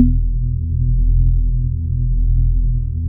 VIBE HIT B-L.wav